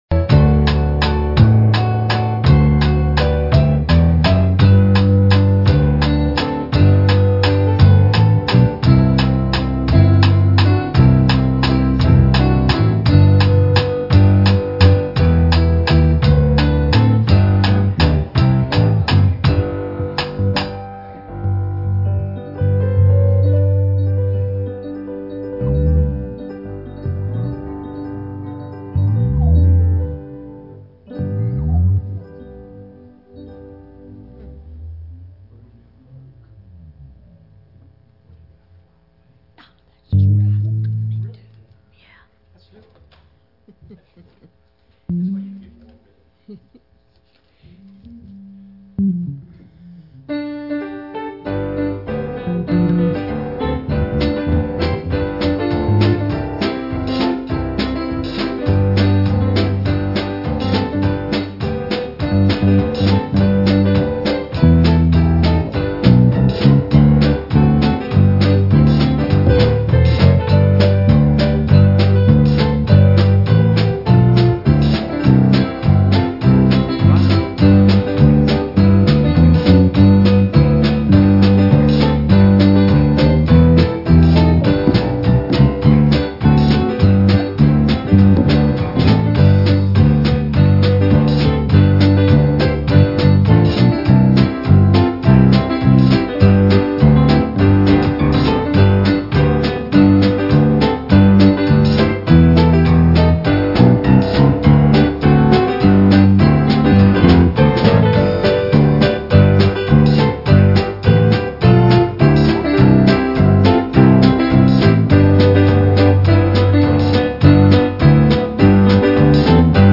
"Matthew 19:16-30" "1 John 2:15-17" Service Type: Wednesday Evening Services Topics